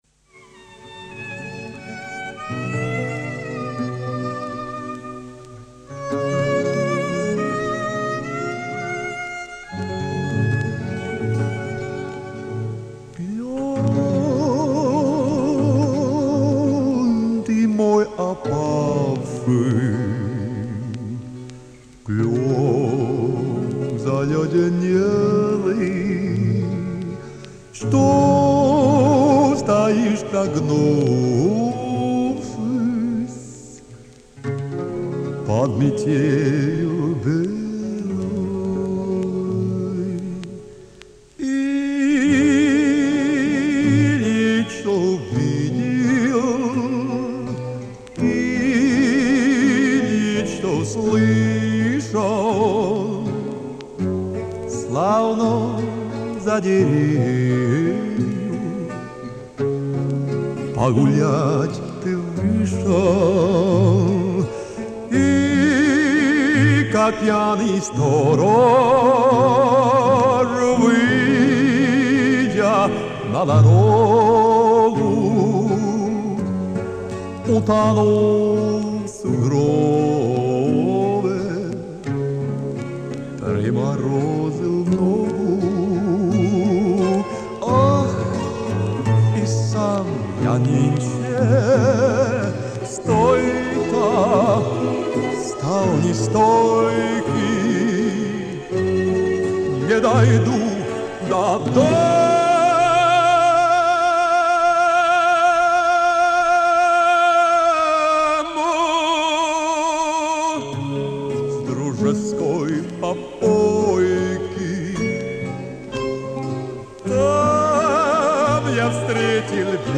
Жанр: Ретро